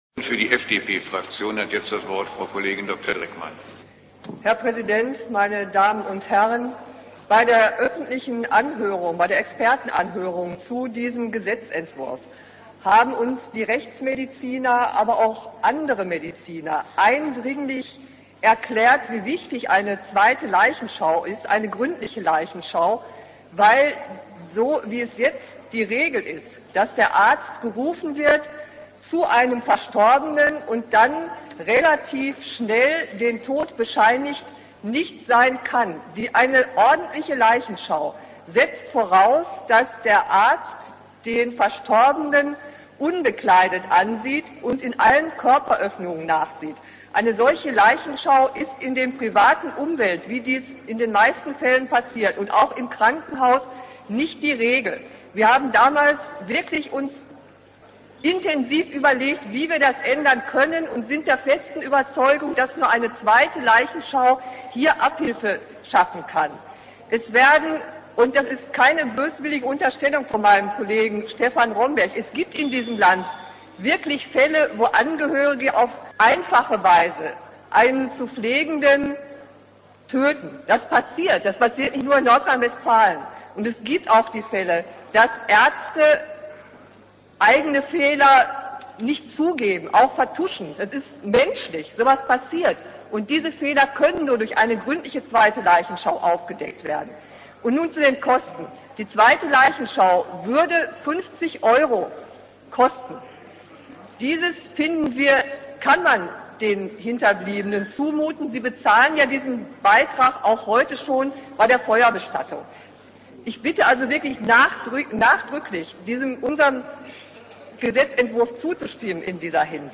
Die sachliche Replik der FDP-Abgeordneten Dr. Ute Dreckmann zur Leichenschau